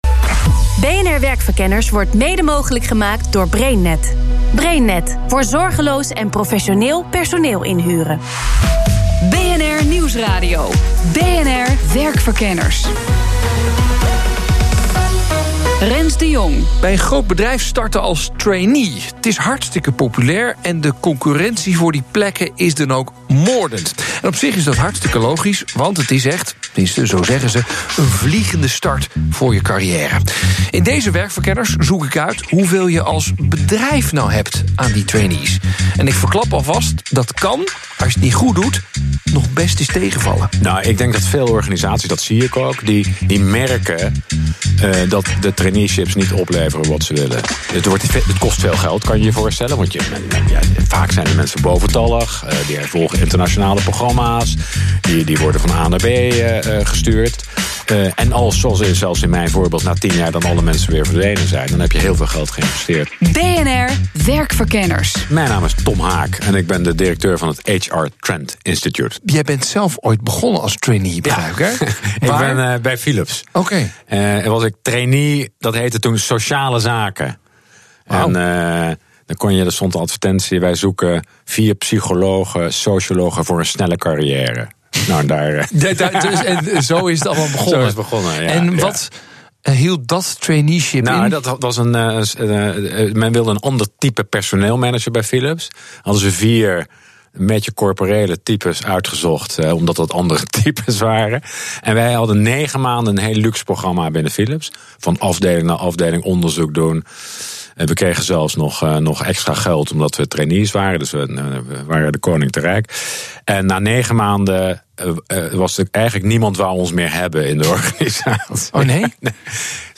radioprogramma